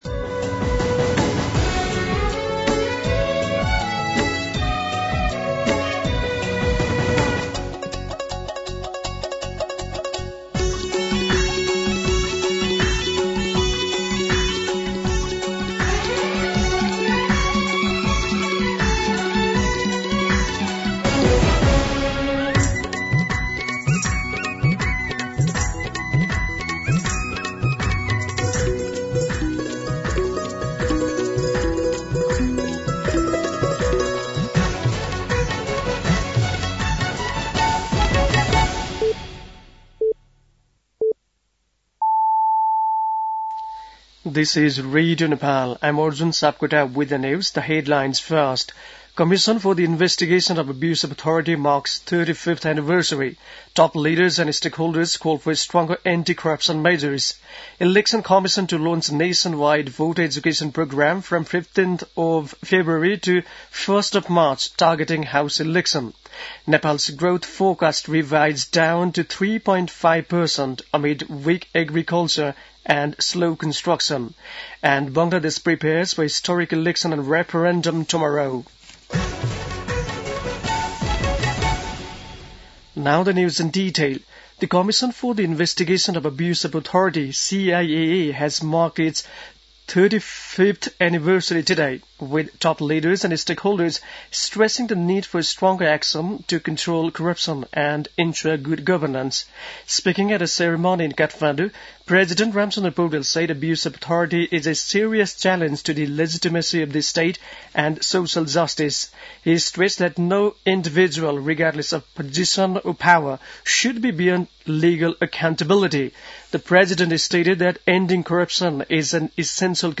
दिउँसो २ बजेको अङ्ग्रेजी समाचार : २८ माघ , २०८२